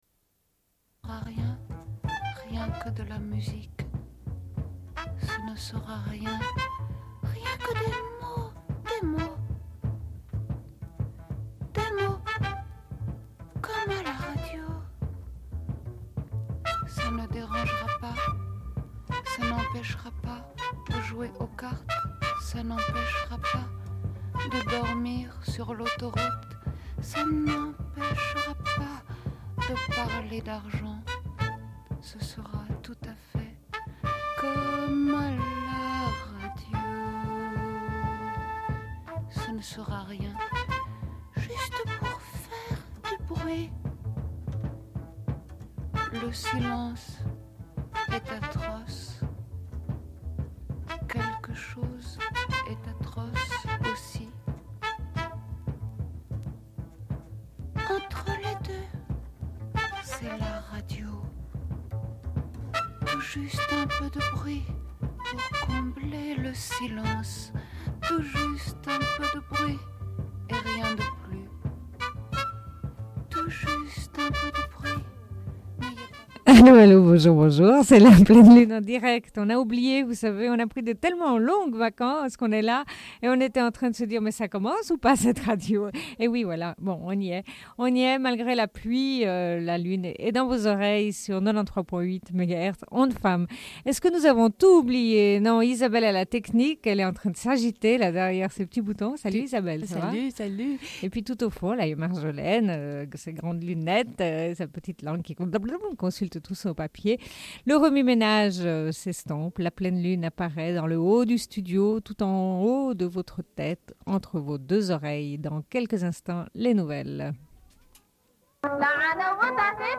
Bulletin d'information de Radio Pleine Lune du 09.09.1992 - Archives contestataires
Une cassette audio, face B29:09